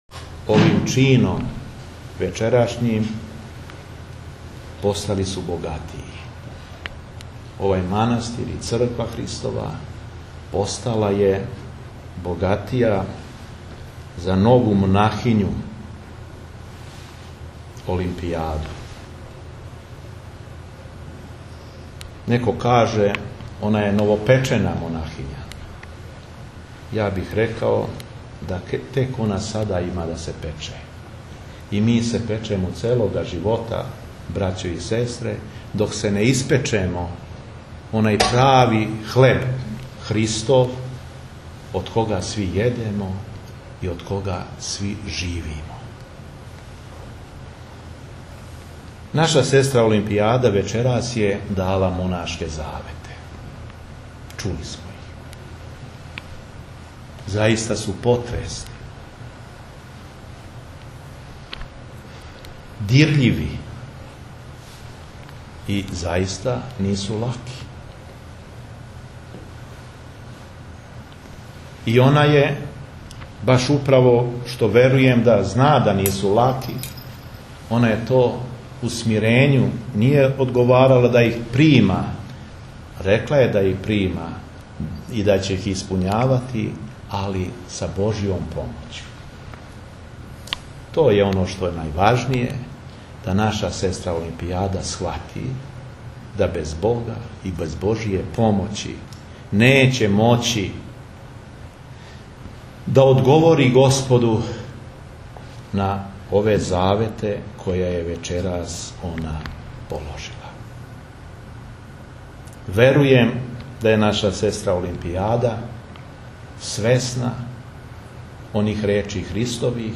Беседа епископа шумадијског и администратора жичког Г. Јована